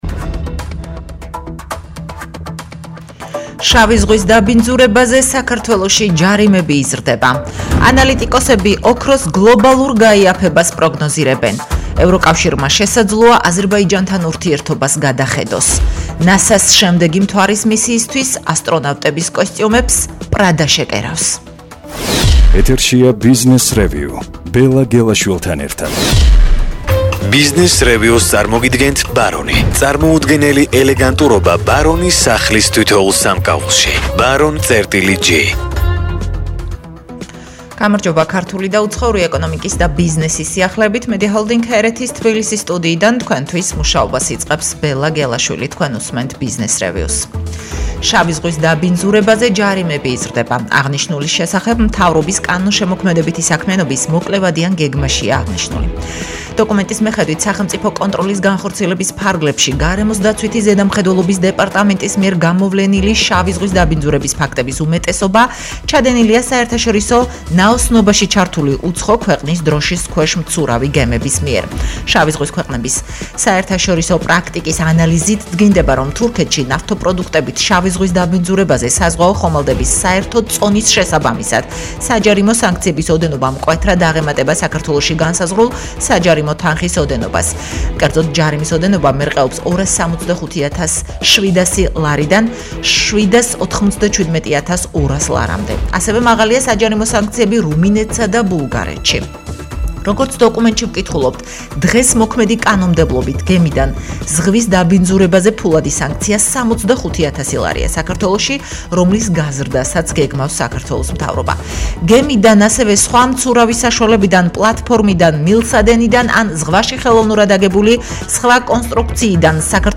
რადიოგადაცემა